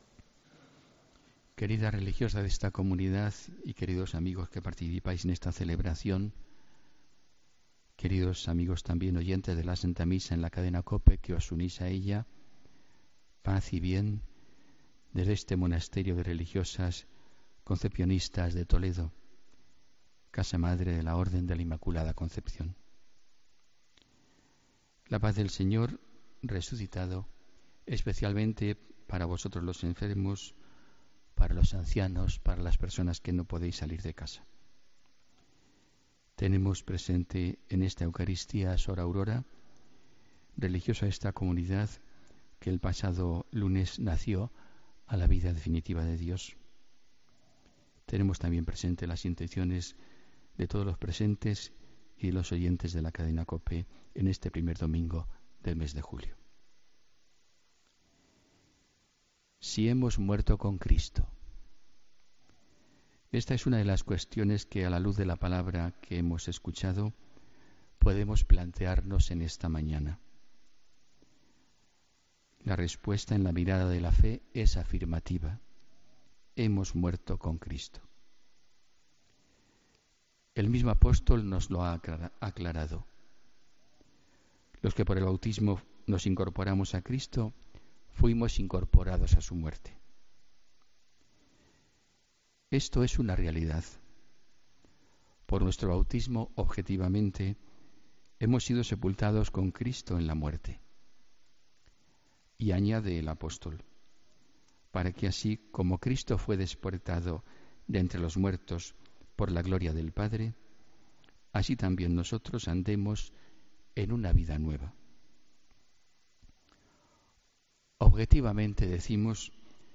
Homilía del domingo 2 de julio de 2017